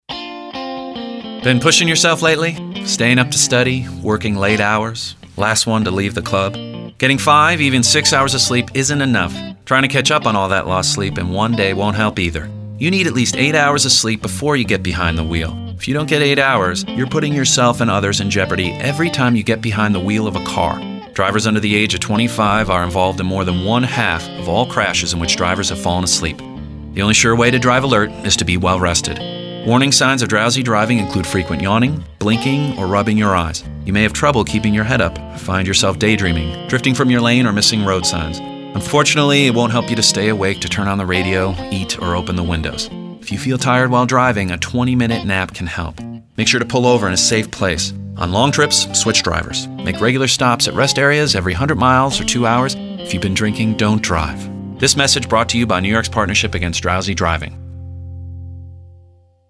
Young Adult :60 Radio PSA.